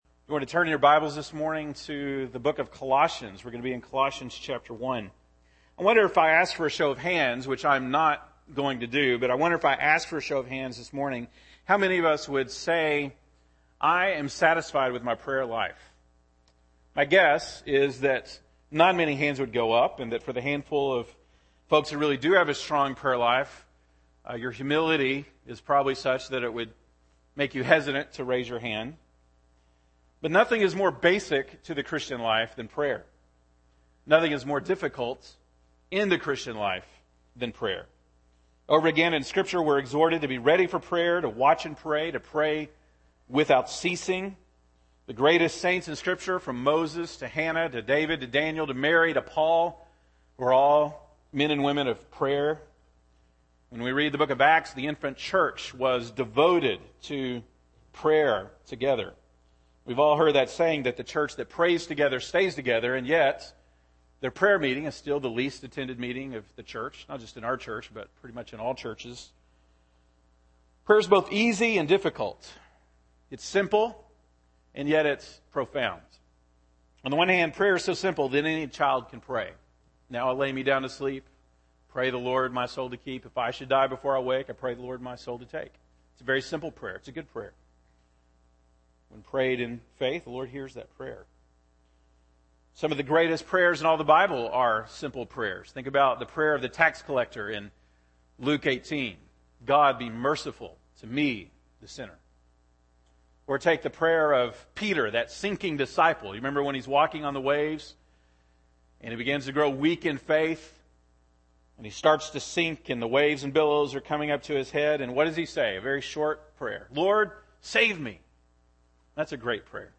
August 3, 2014 (Sunday Morning)